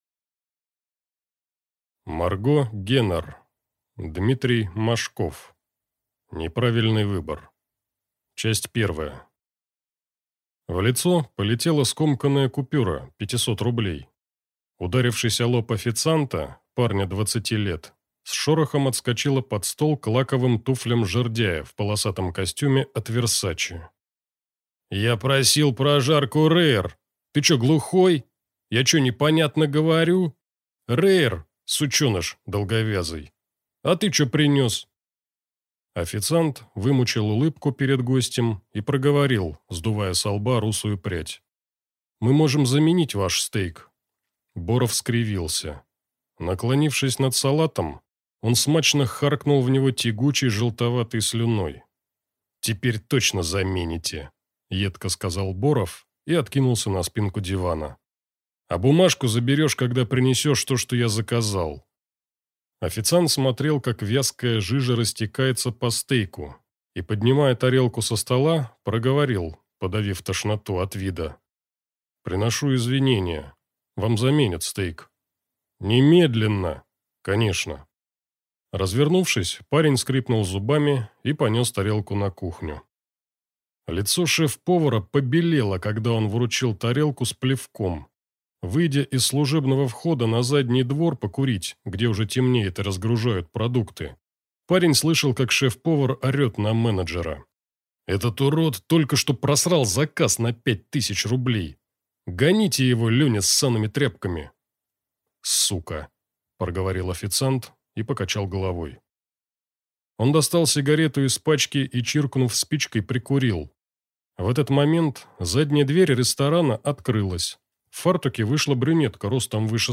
Aудиокнига